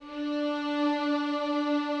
strings_050.wav